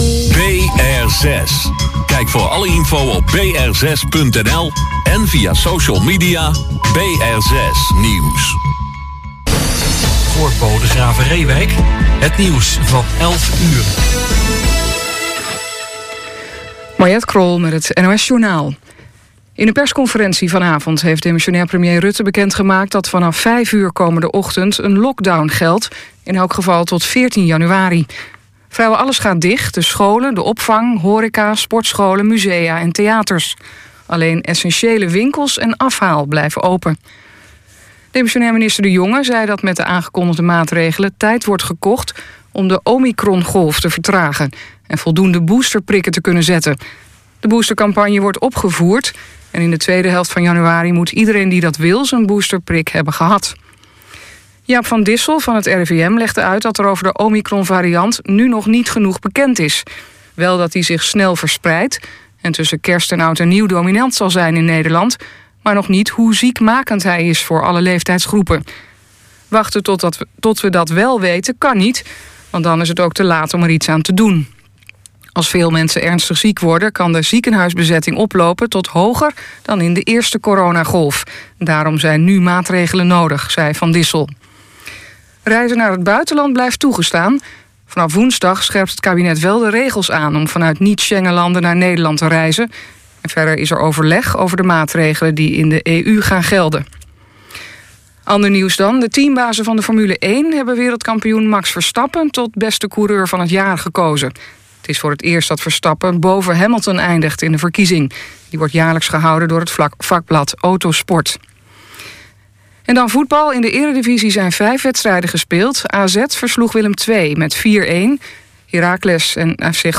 “Riverside Jazz” wordt elke zaterdagavond uitgezonden via BR6, van 22:00 tot 00:00 uur.